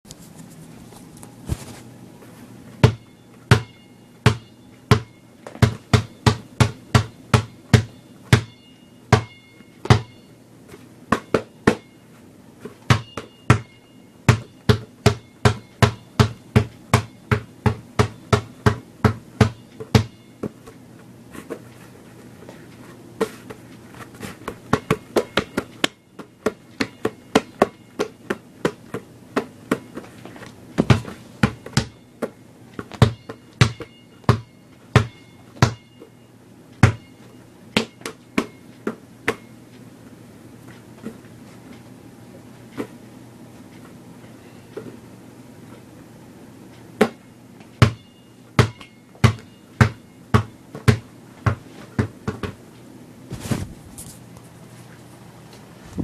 A basketball bouncing on dorm room tile.